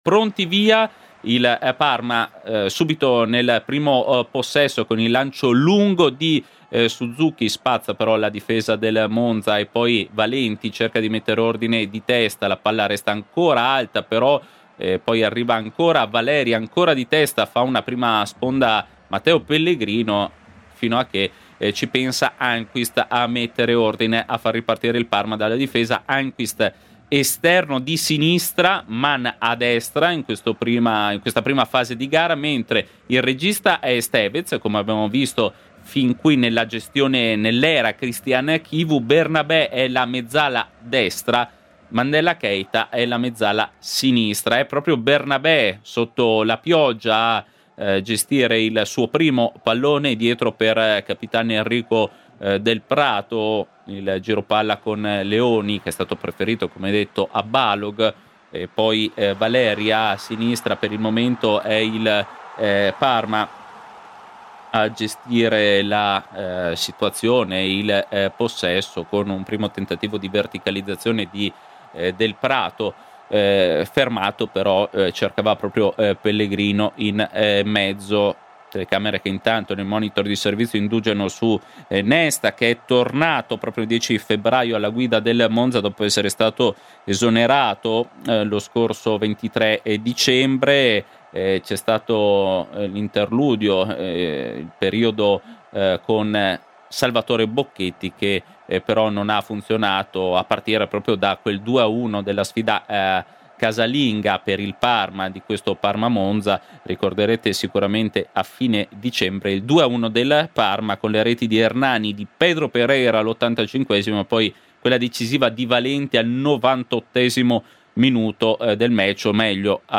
Monza-Parma è una partita che per i crociati pesa decisamente sulle prospettive di salvezza. Radiocronaca